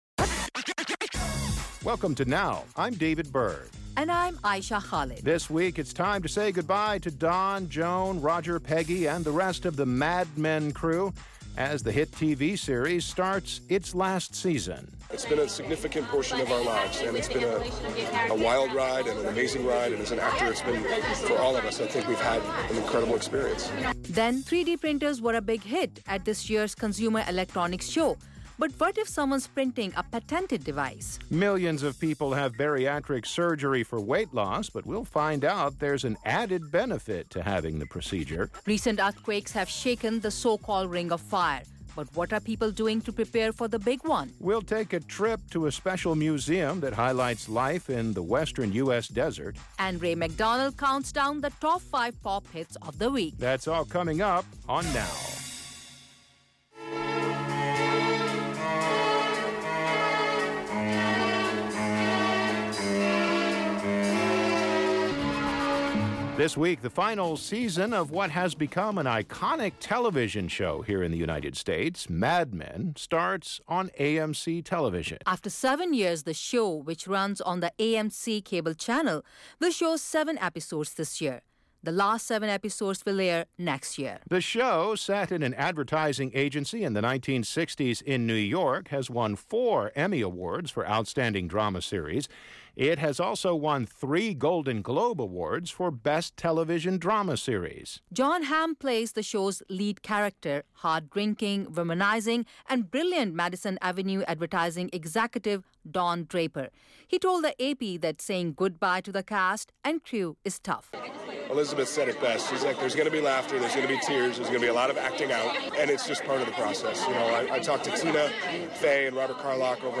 NOW! is a multi-media conversation between you and program hosts